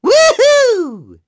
Neraxis-Vox_Happy4.wav